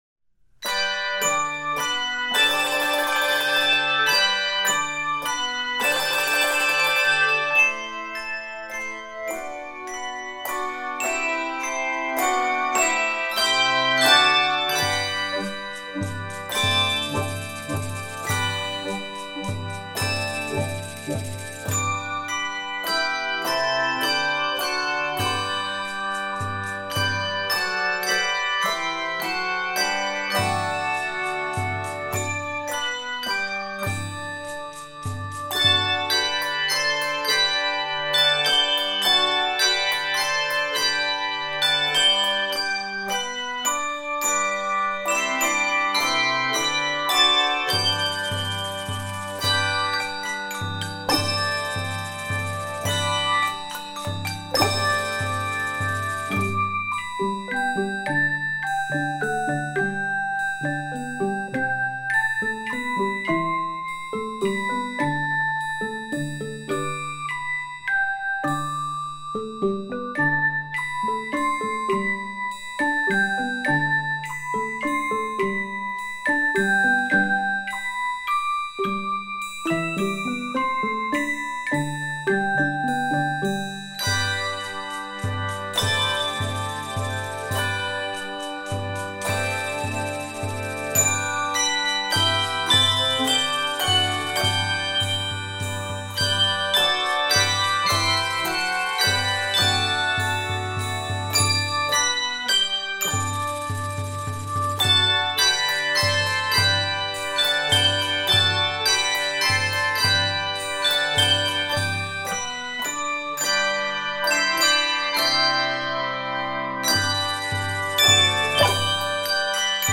happy and accessible setting
Percussion parts included. 75 measures. Key of G Major.